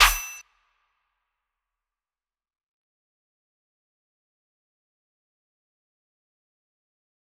DMV3_Clap 6.wav